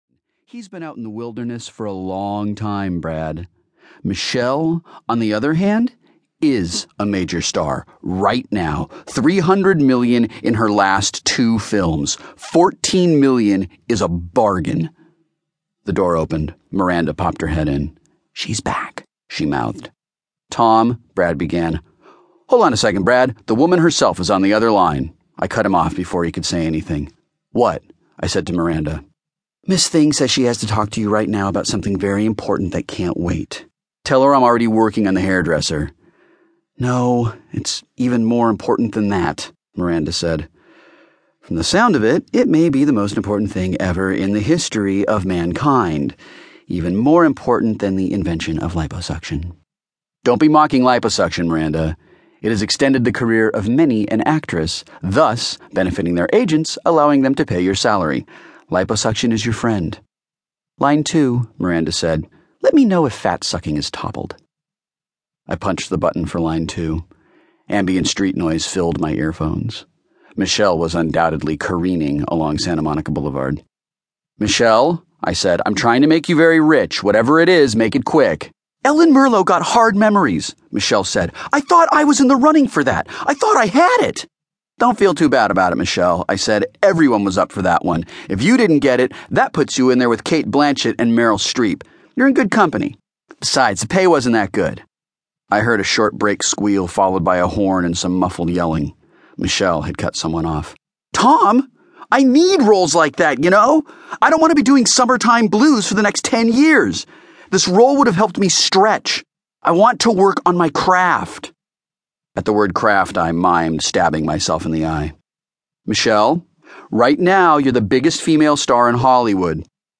• Audiobook